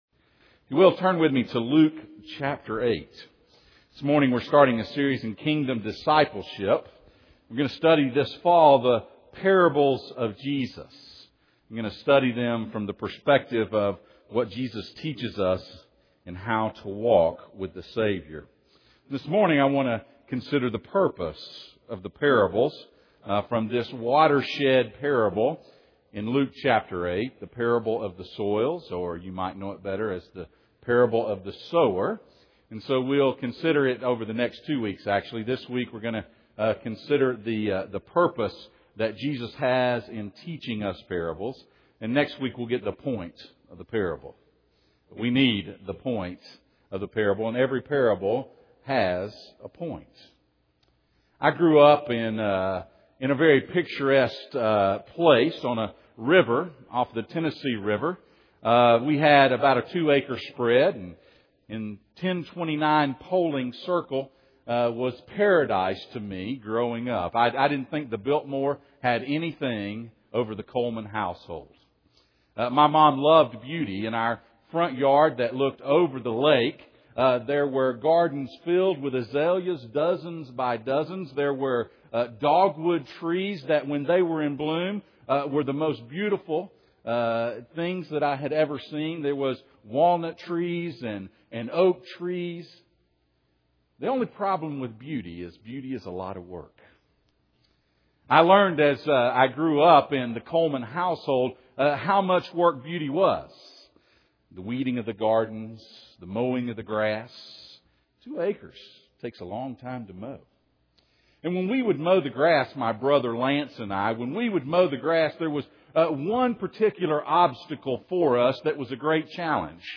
Kingdom Discipleship Passage: Luke 8:14-16 Service Type: Sunday Morning « Praying After The Master Life Together